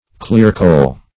Clearcole \Clear"cole`\, n. [F. claire colle clear glue; clair